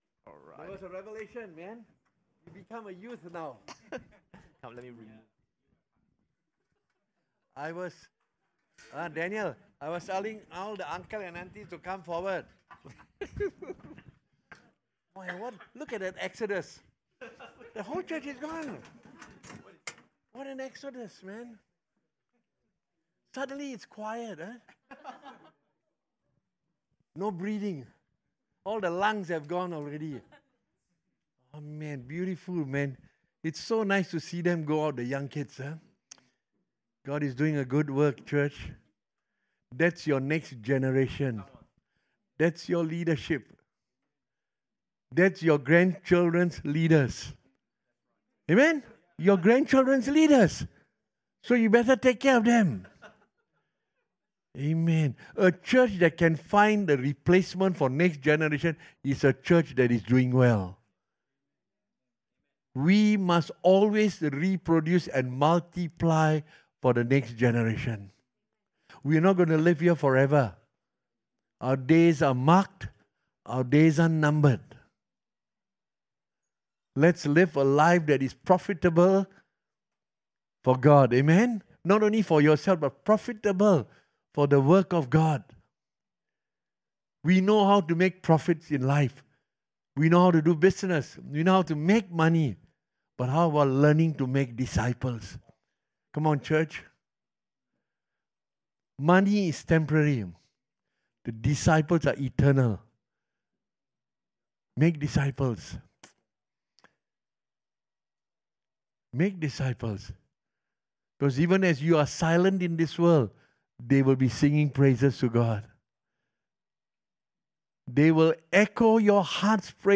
English Sermons | Casey Life International Church (CLIC)
2025 CLIC FAMILY CAMP